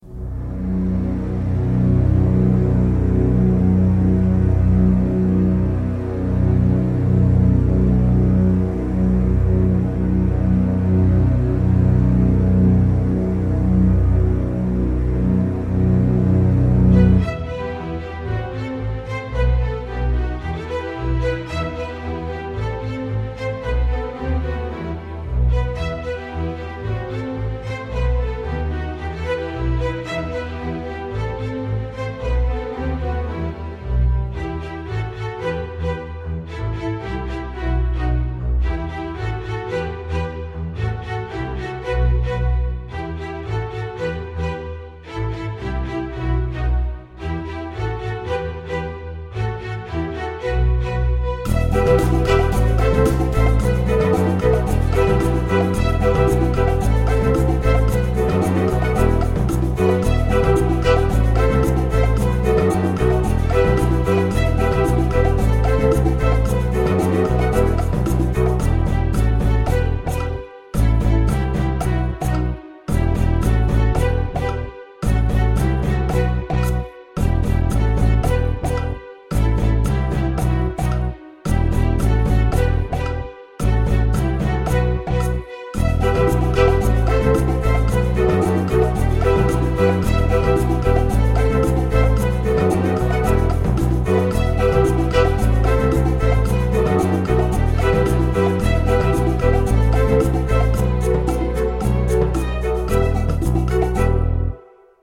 These files are for you to hear what the various accompanying forces sound like and for your practice and memorization.
A La Media Noche (Puerto Rican Carol)